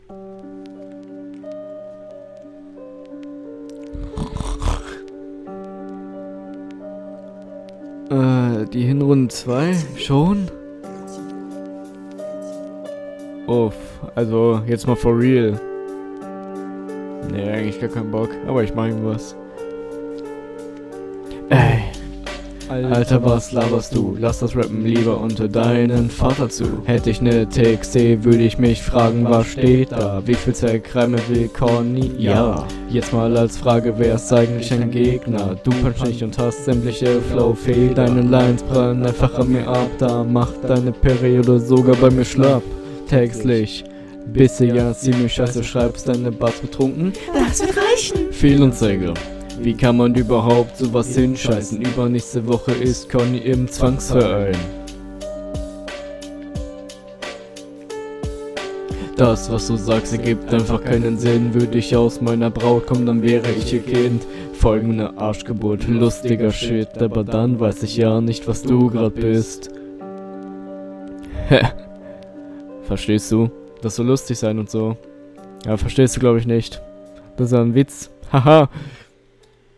Flow: bei dir genau das gleiche Bild, versuch druckvoller zu rappen und mehr auf den …
Flow: Flowlich ist die Runde ganz okay, auch hier wieder der Stimmeinsatz leider nicht stark …